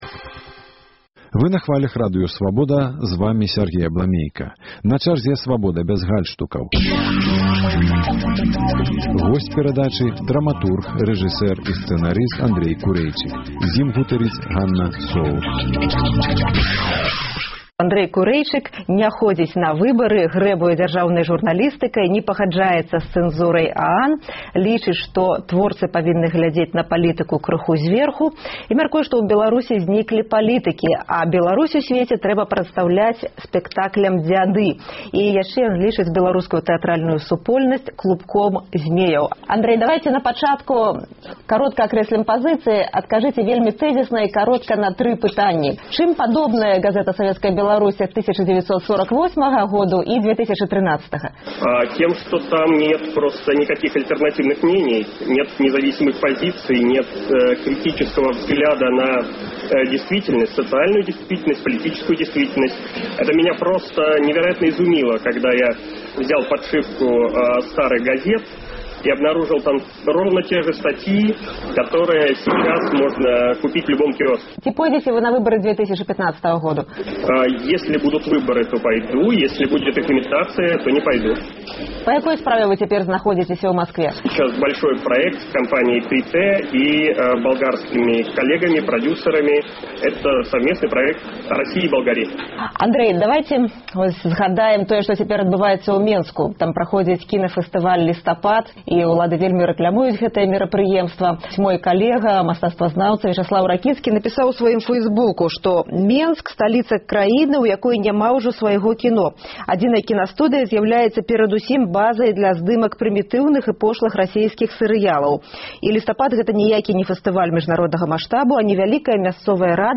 Абмеркаваньне гарачых тэмаў у студыі Свабоды.